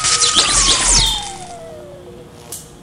beambroken.wav